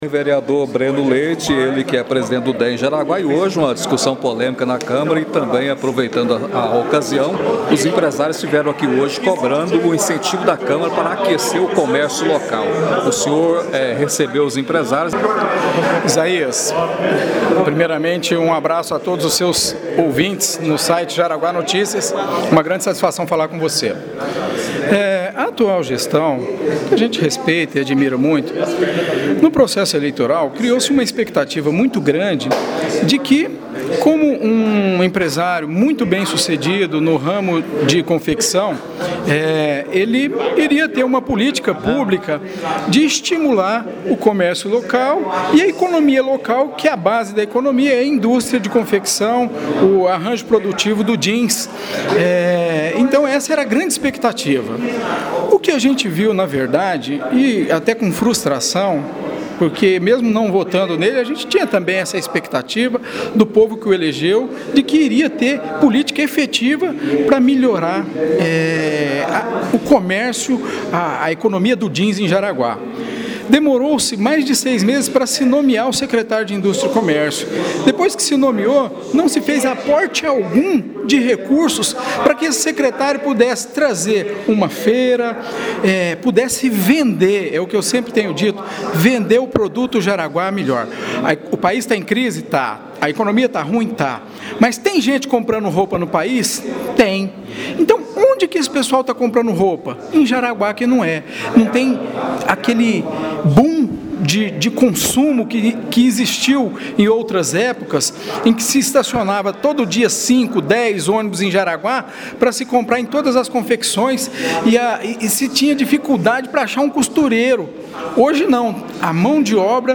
Outras ações em nível de município também foi questionada pelo vereador, cuja declaração pode ser ouvida na entrevista em áudio.